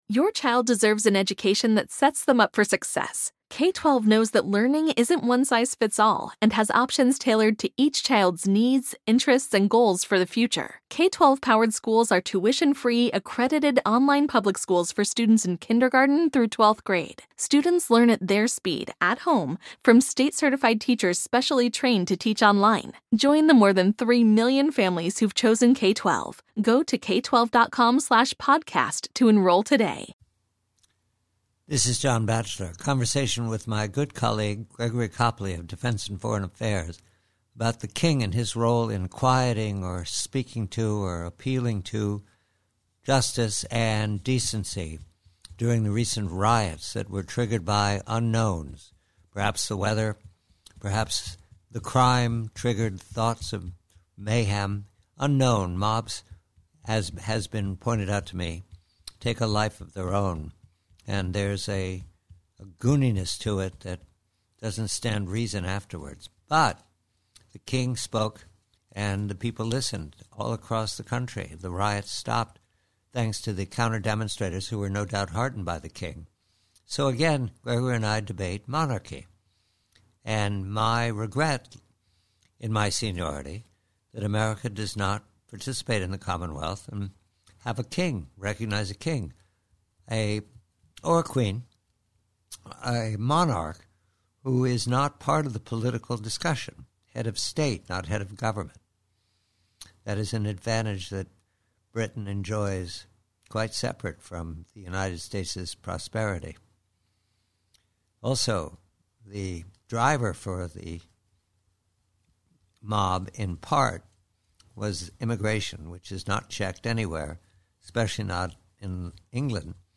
KING CHARLES: RIOTERS: Conversation